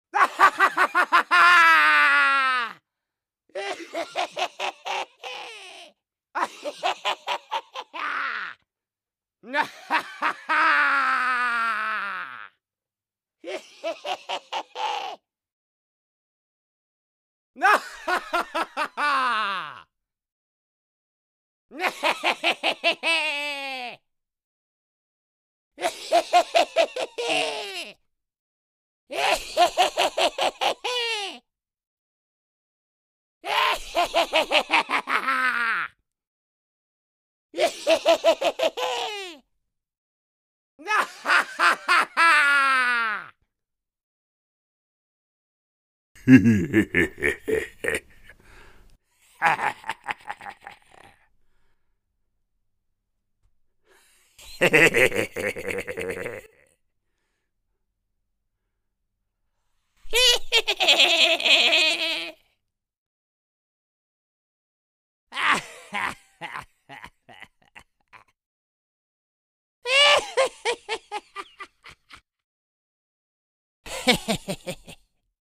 Звуки монстров хижной живности и других страшных тварей существующих и нет...